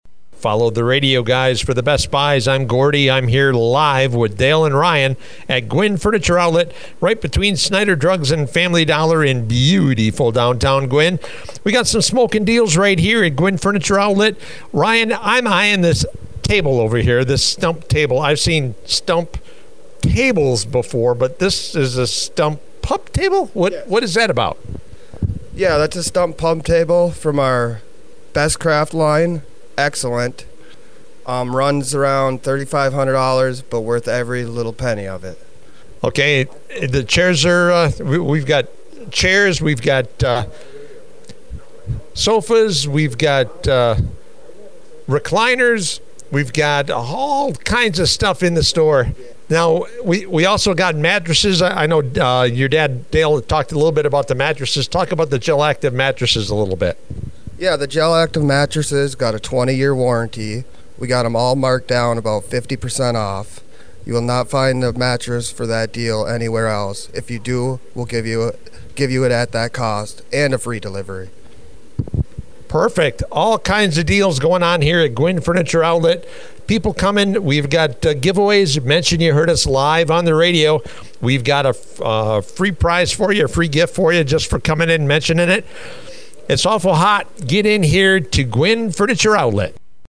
Live from the Gwinn Furniture Outlet!